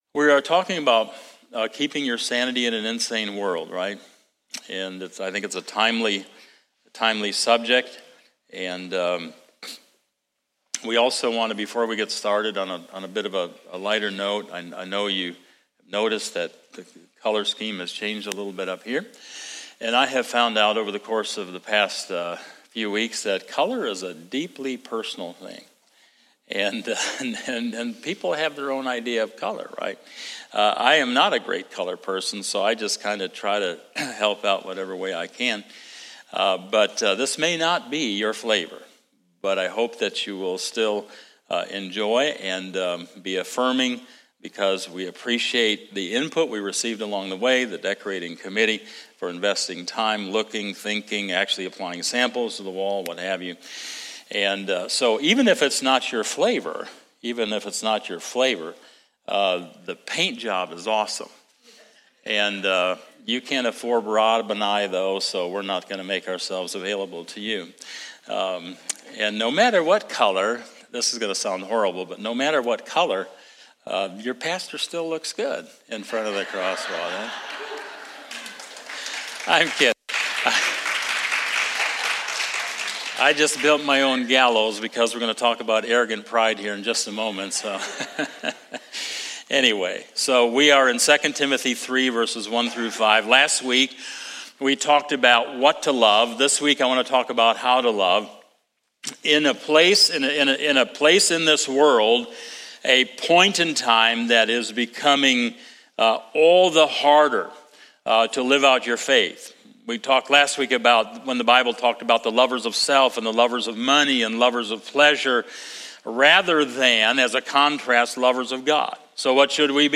Sermon-8-28-22.mp3